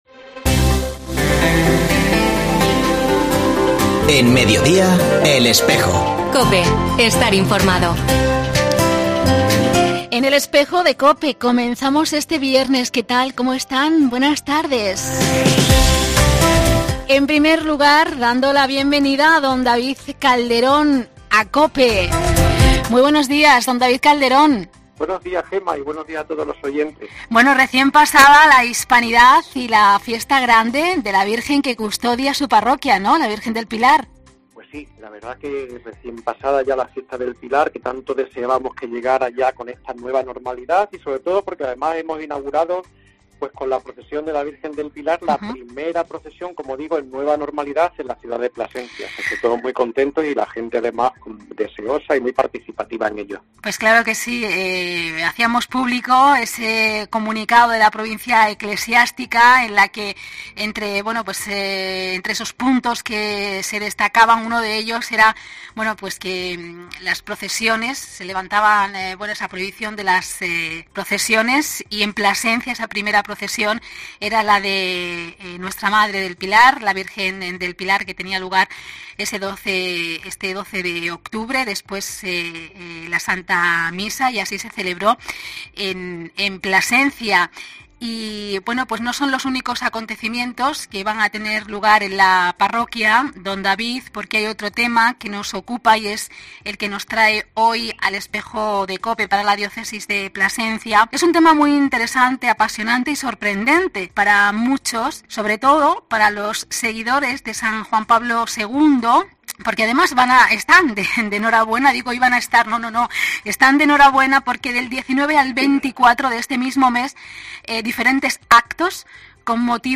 Los micófonos de Cope tambien han estado presente en el día en el que la "Cruz de los Jovenes" visitó Plasencia.